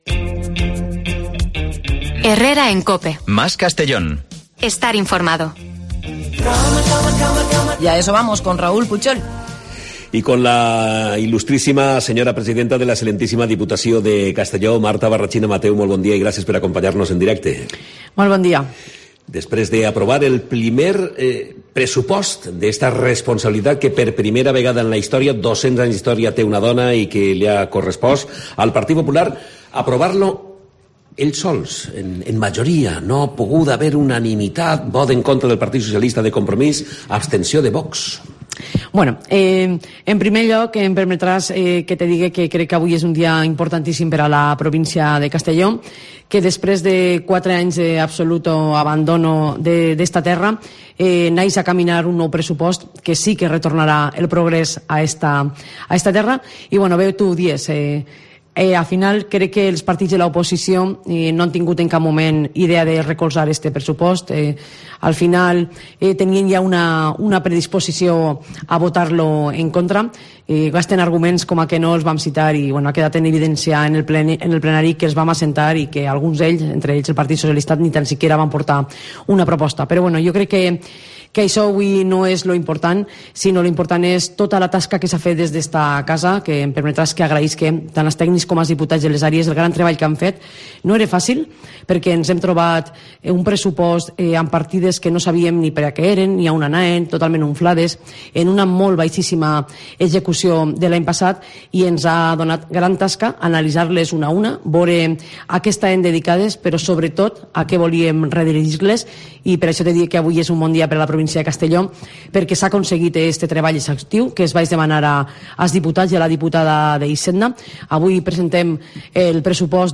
La presidenta de la Diputació de Castelló pasa por los micrófonos de COPE tras aprobar unas cuentas de 193,7 millones de euros